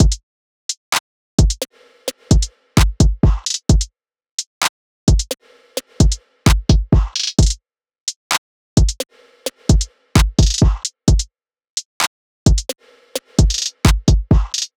SOUTHSIDE_beat_loop_lime_full_01_130.wav